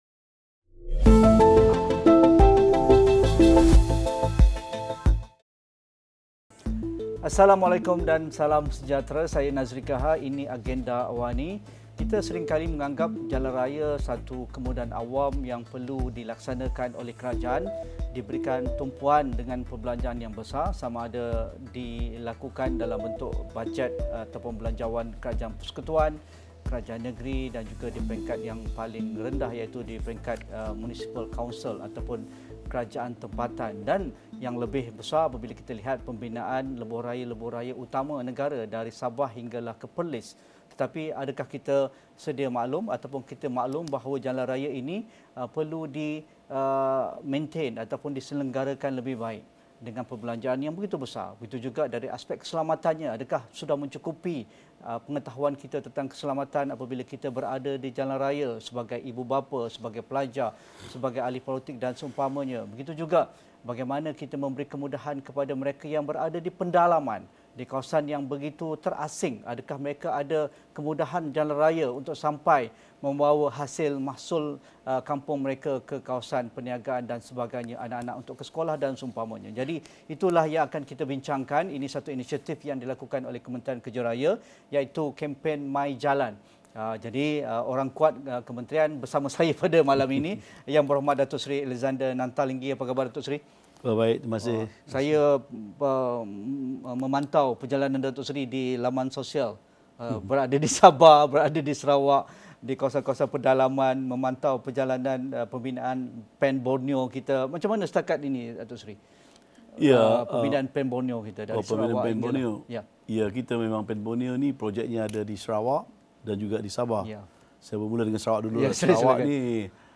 Kempen MYJalan adalah merupakan inisiatif baharu Kementerian Kerja Raya bagi memberi lebih perhatian kepada isu-isu melibatkan infra jalan raya. Temu bual khas bersama Menterinya, Datuk Seri Alexander Nanta Linggi.